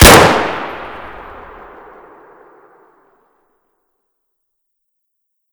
sounds / weapons / sks / shoot.ogg
shoot.ogg